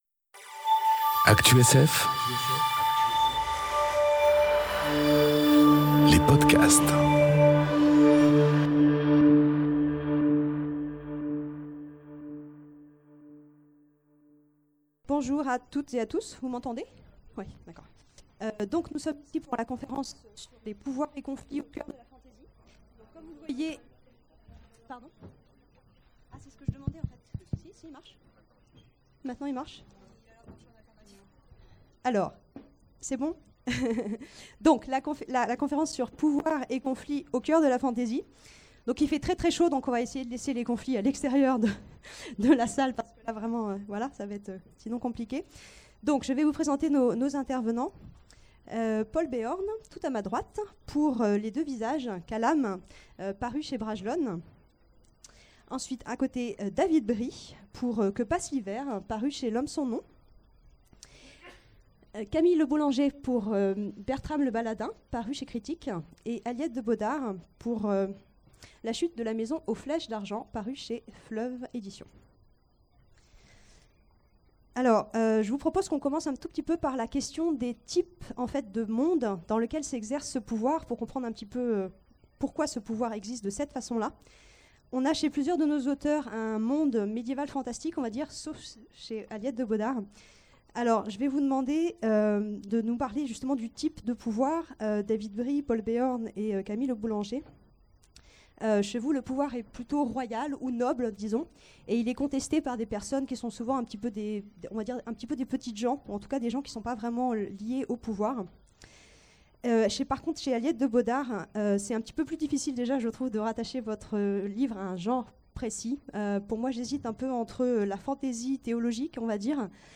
Conférence Pouvoirs et conflits... Au cœur de la fantasy ! enregistrée aux Imaginales 2018